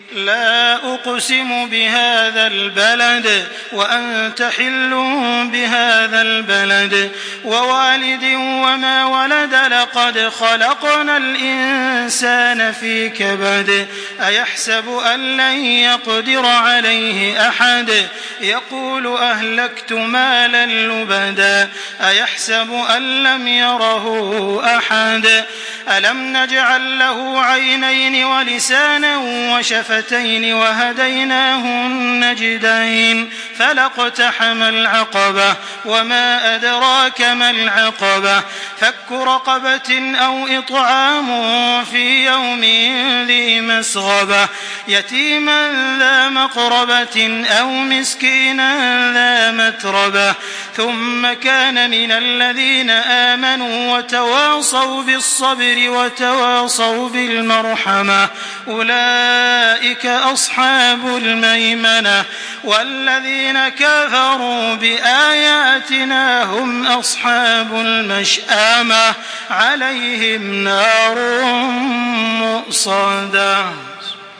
Makkah Taraweeh 1425
Murattal